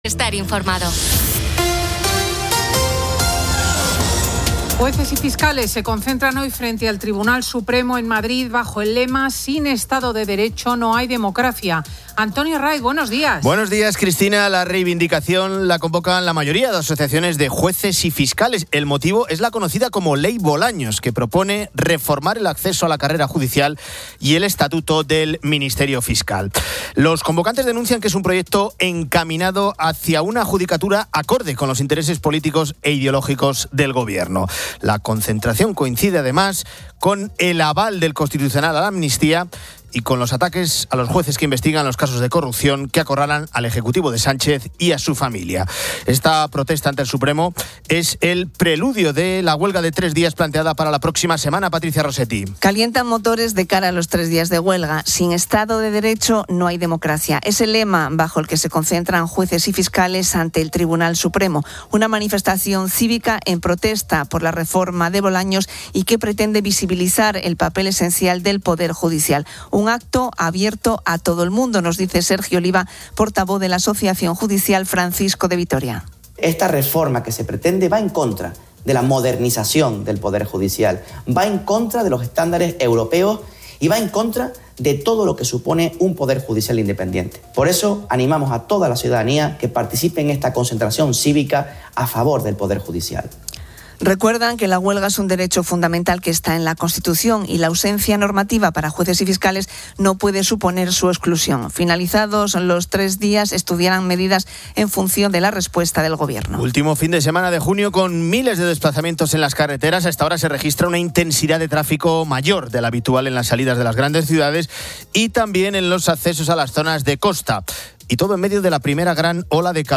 Fin de Semana 10:00H | 28 JUN 2025 | Fin de Semana Editorial de Cristina López Schlichting.